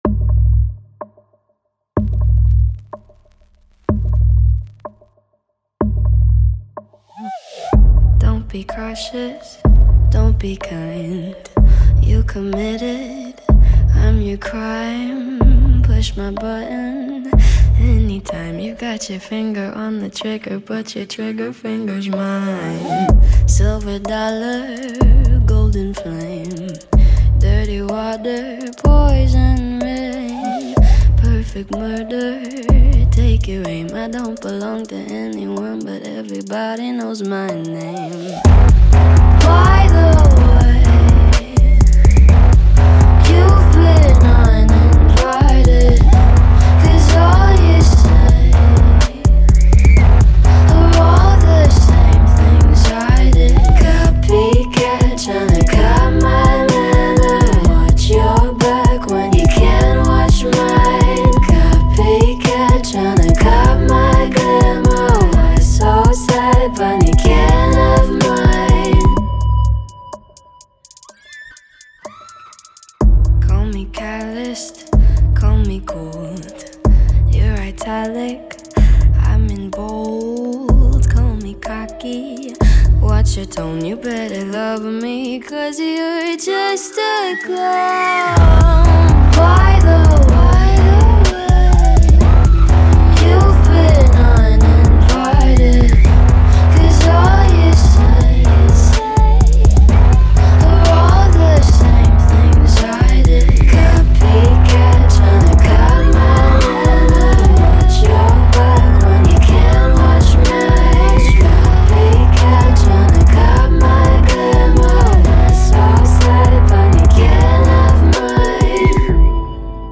آلبوم پاپ آلترناتیو
با ریتم‌های تند و اشعار انتقادی
Alternative Pop